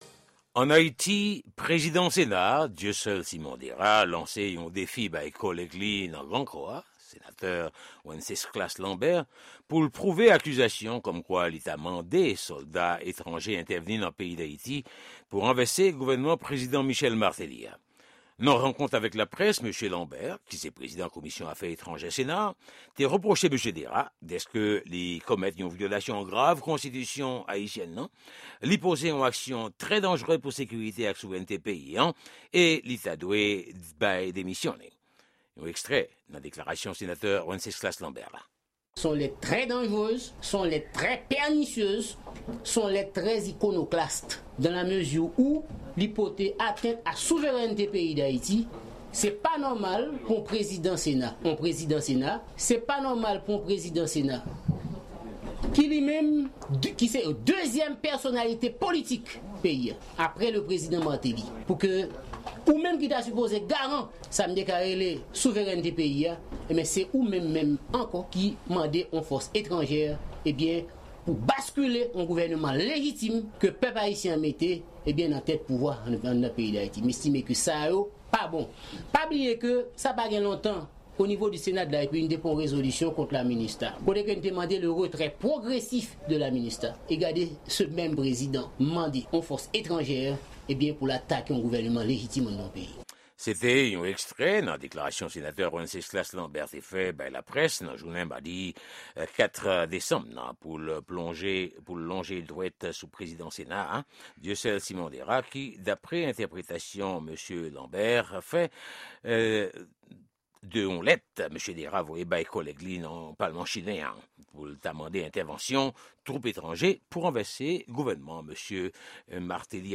Yon repòtaj Lavwadlamerik